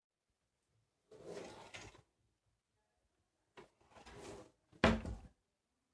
推拉门衣柜
描述：推拉门衣柜开合。